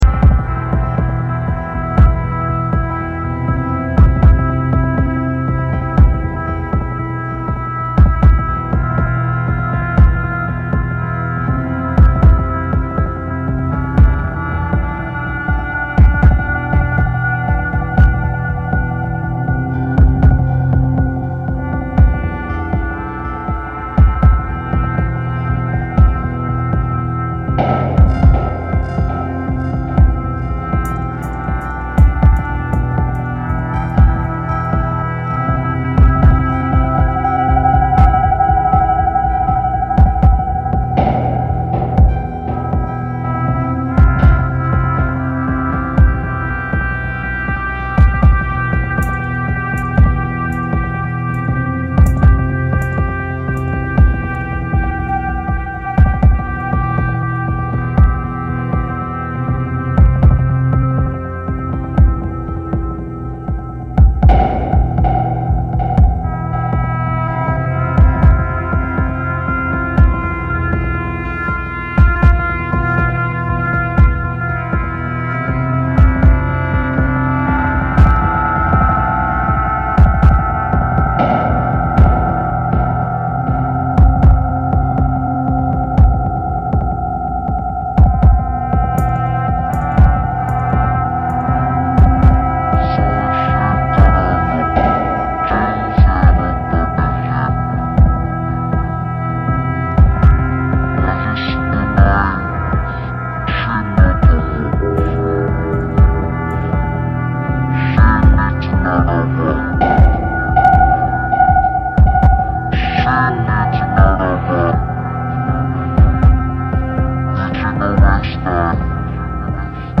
mournful
Electronix Techno Wave